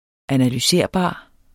Udtale [ analyˈseɐ̯ˀˌbɑˀ ]